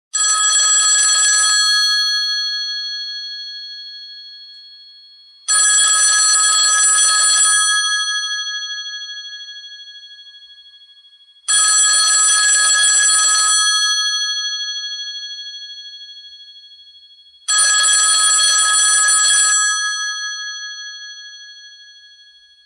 Звуки старого телефона
Здесь вы найдете классические звонки, гудки и сигналы винтажных аппаратов, которые когда-то были неотъемлемой частью повседневной жизни.
Звон старого механического телефона